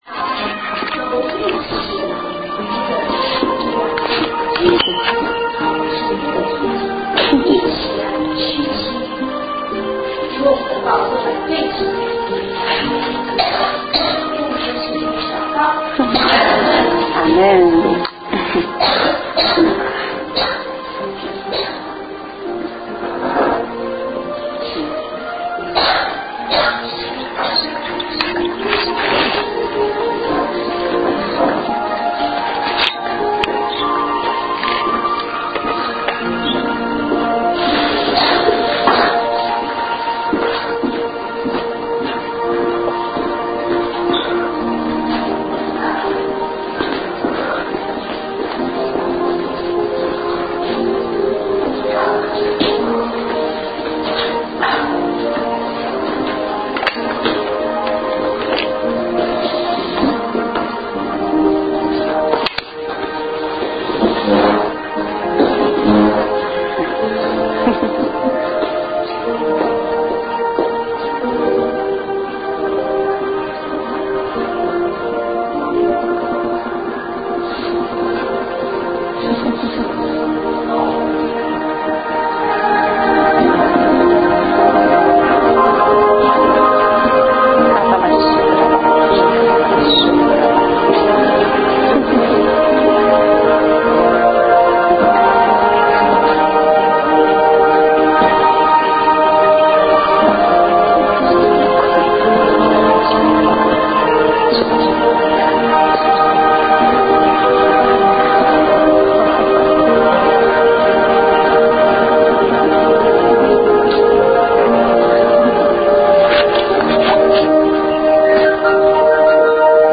正在播放：--主日恩膏聚会录音（2014-09-21）